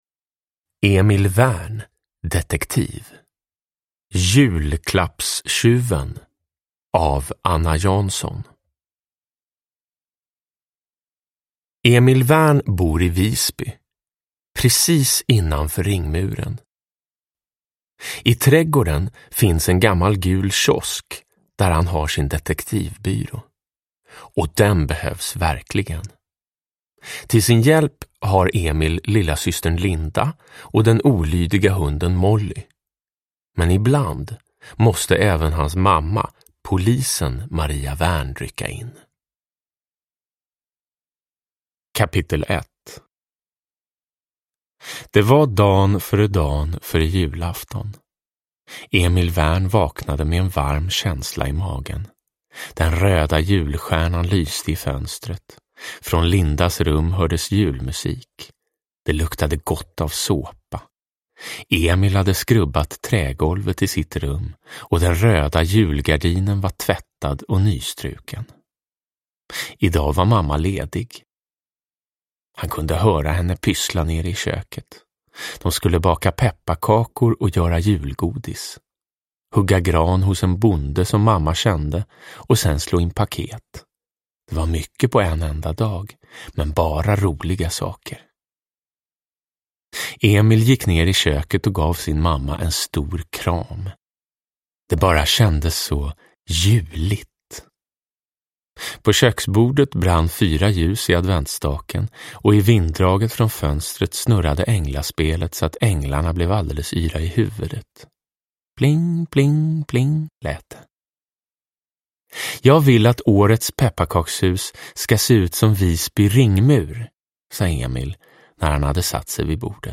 Julklappstjuven – Ljudbok – Laddas ner
Uppläsare: Jonas Karlsson